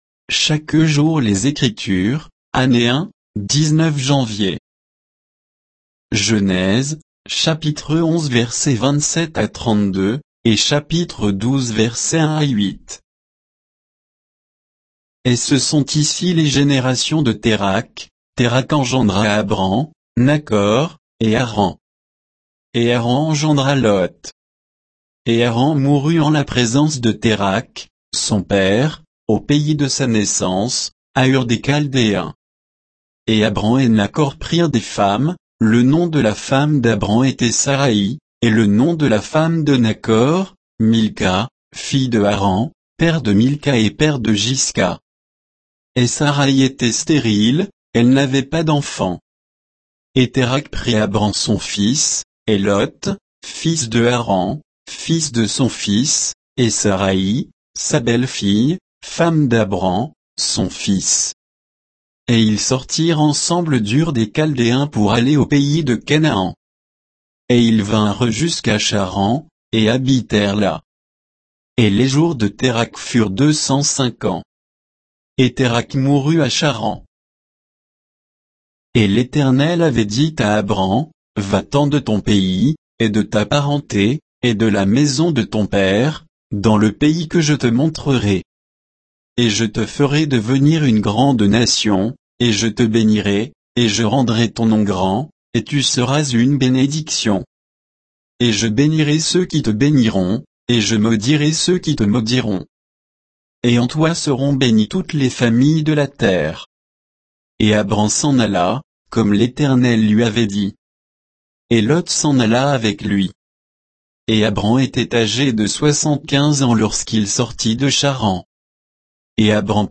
Méditation quoditienne de Chaque jour les Écritures sur Genèse 11